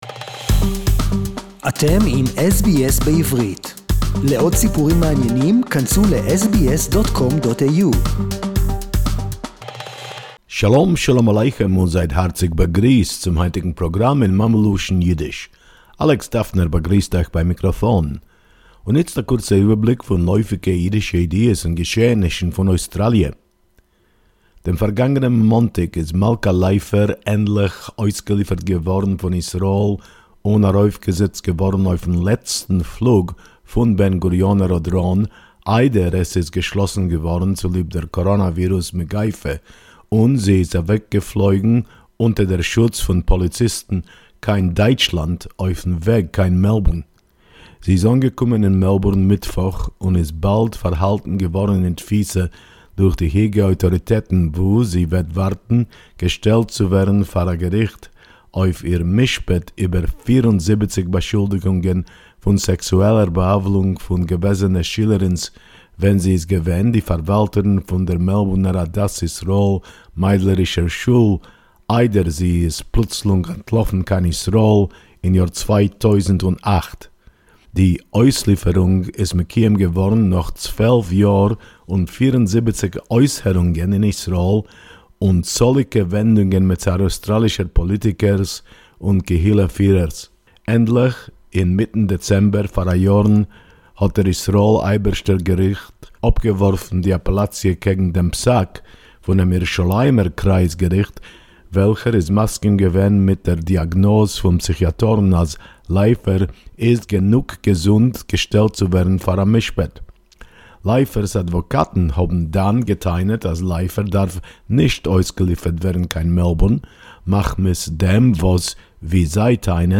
SBS weekly Yiddish report